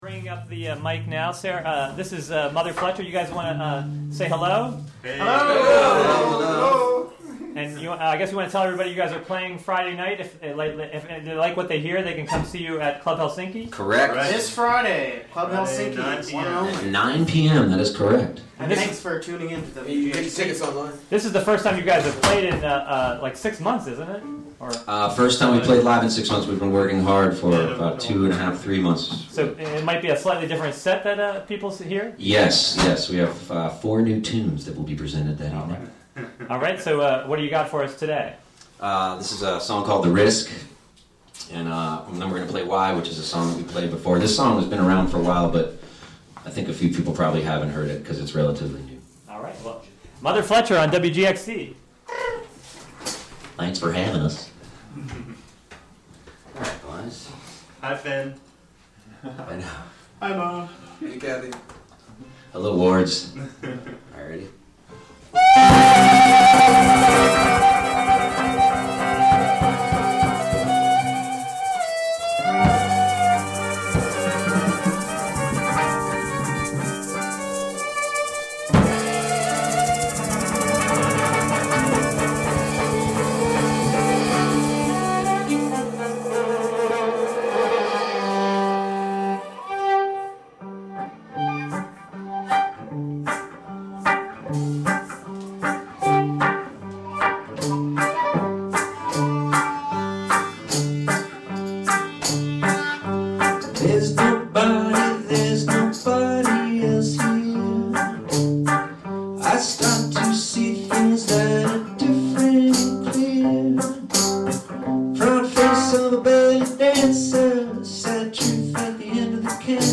Live performance during Spring Pledge Drive.